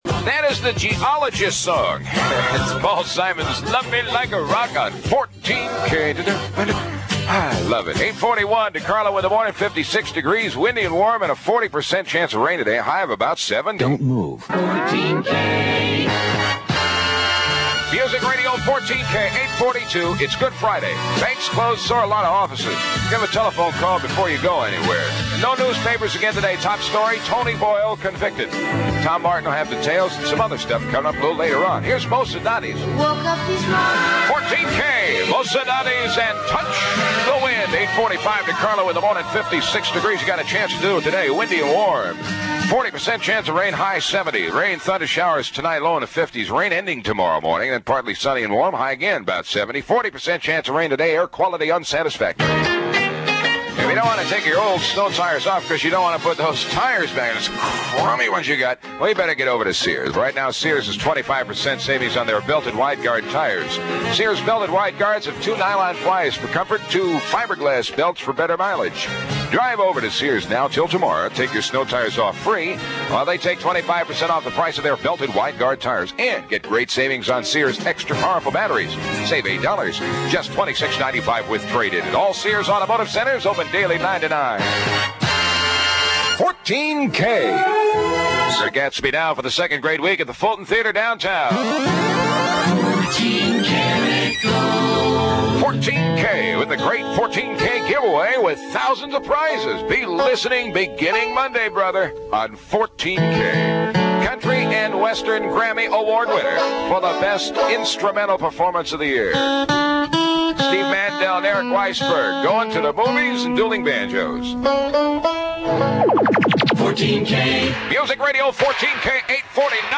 14K Aircheck
April 12, 1974 (Good Friday)   PAMS Custom 14K Musicradio Jingles